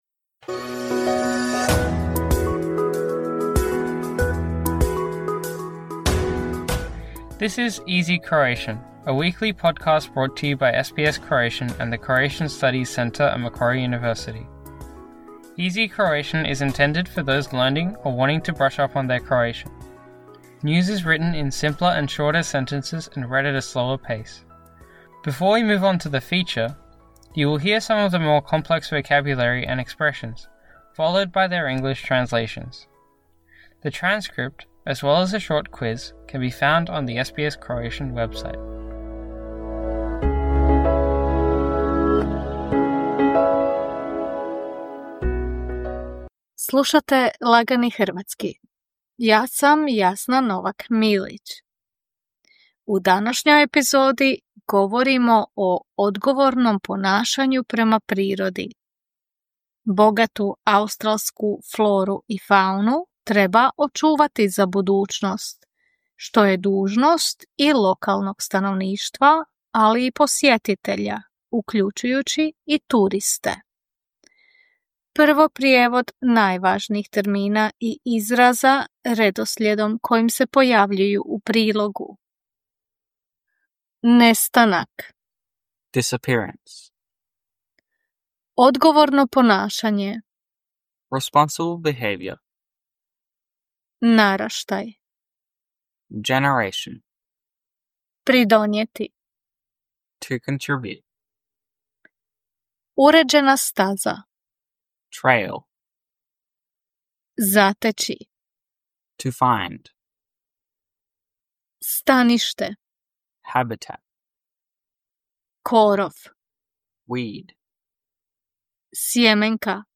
Before we move on to the feature, you will hear some of the more complex vocabulary and expressions, followed by their English translations.
News is written in simpler and shorter sentences and read at a slower pace.…